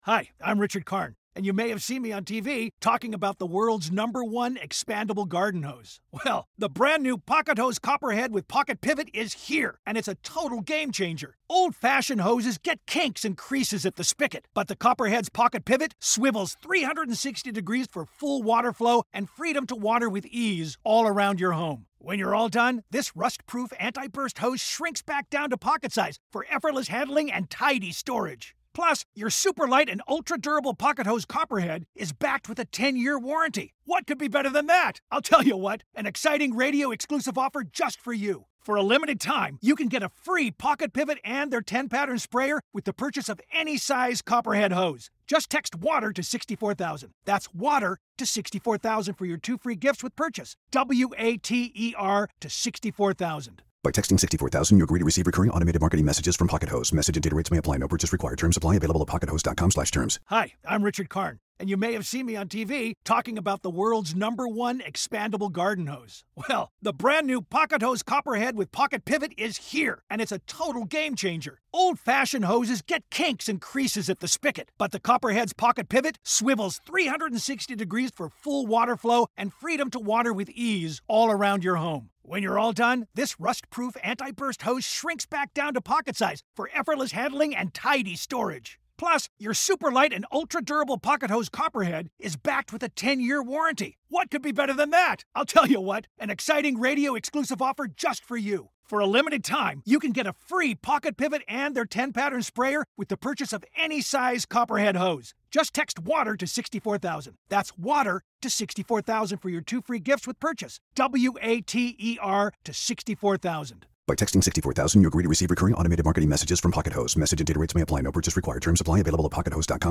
Ollie Bearman, Mika Hakkinen + F1 Explains listeners LIVE at the US Grand Prix
A Formula 1 legend and a brand new star took to the stage at Circuit of the Americas for F1 Explains LIVE.